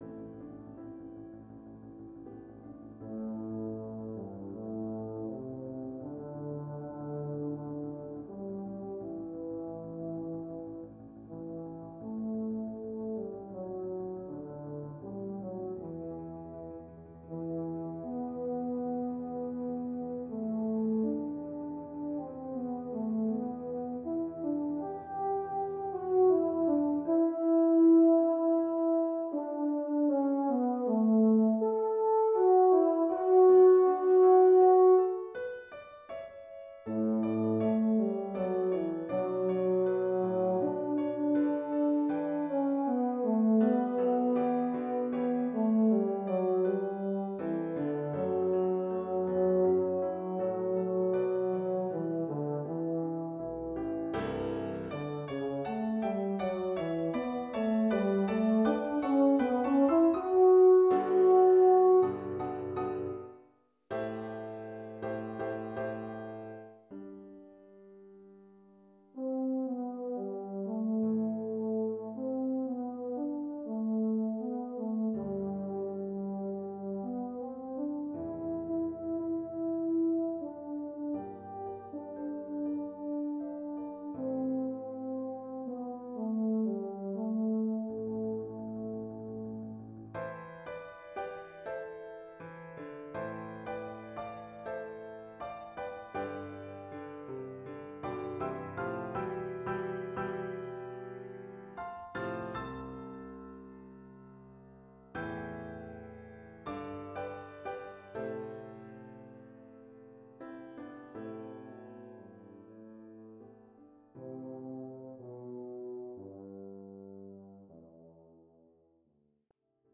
Voicing: Euphonium Solo